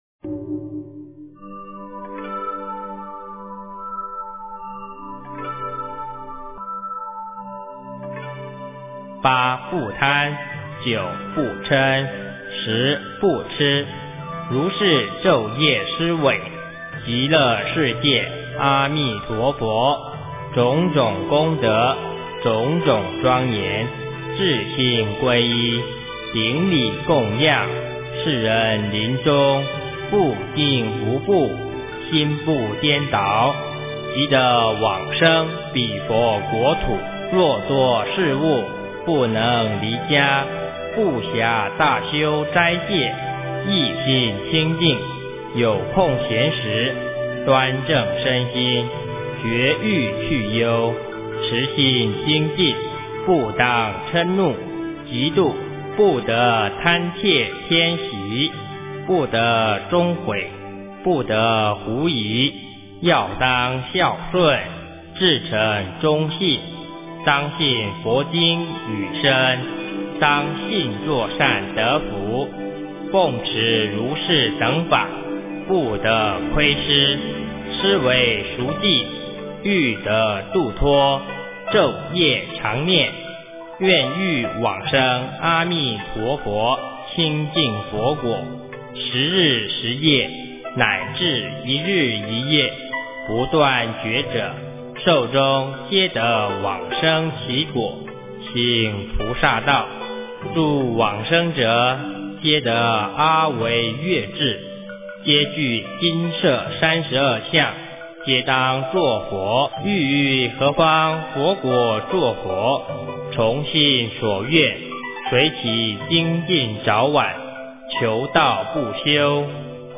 诵经
佛音 诵经 佛教音乐 返回列表 上一篇： 无量寿经-上 下一篇： 地藏经-分身集会品第二 相关文章 我是佛前一朵青莲--任妙音 我是佛前一朵青莲--任妙音...